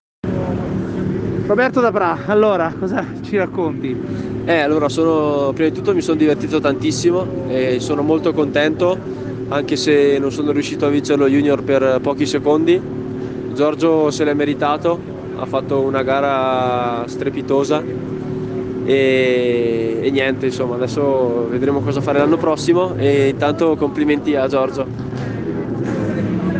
Interviste Rally Liburna Terra 2021
Sabato - Interviste finali